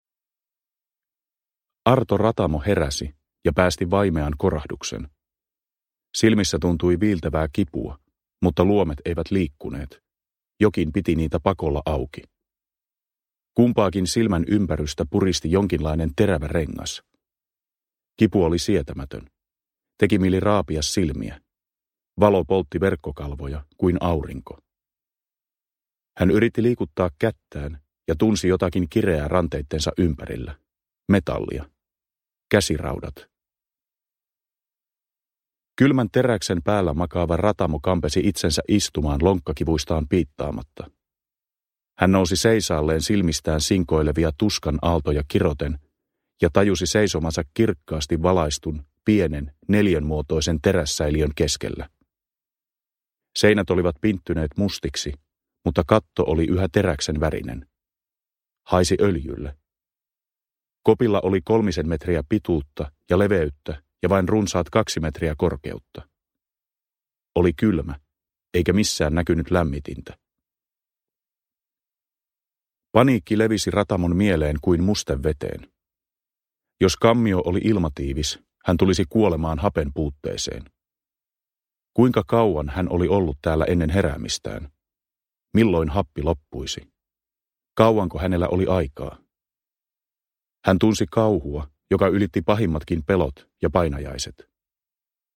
Teräsarkku (ljudbok) av Taavi Soininvaara